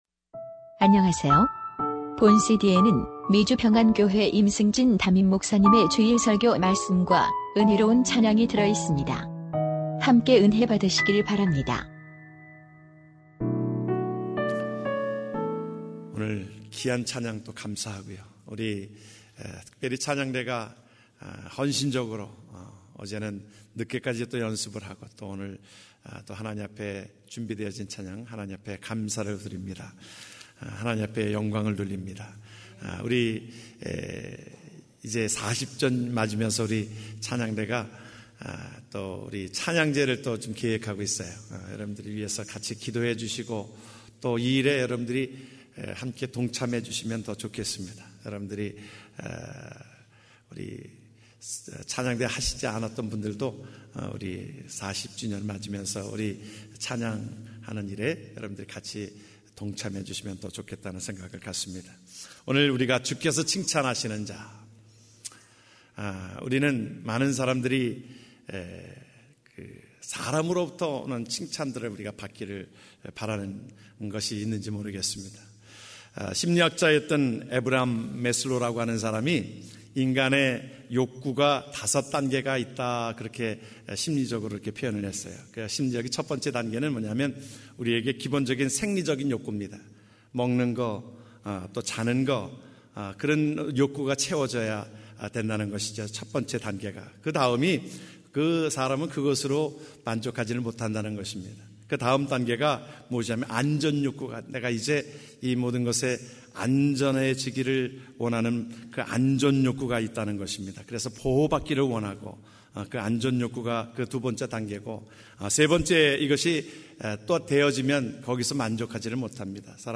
2015년 2월 1일 미주평안교회 주일설교말씀